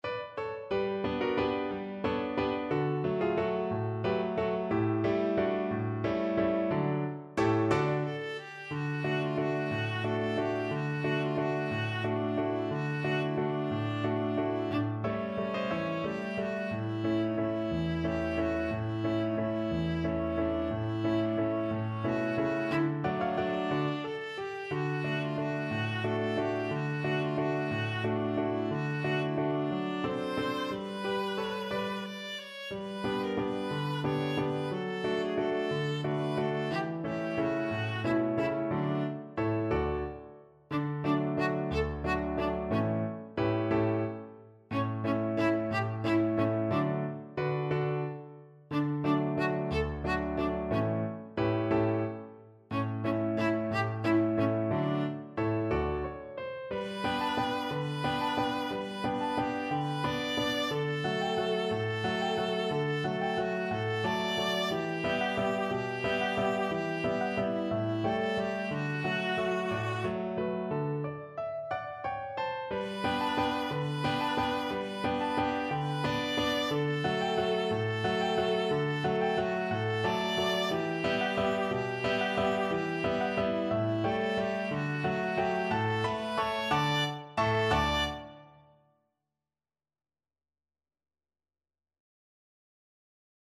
Viola version
Viola
Traditional Music of unknown author.
D major (Sounding Pitch) (View more D major Music for Viola )
One in a bar .=c.60
3/4 (View more 3/4 Music)
Mexican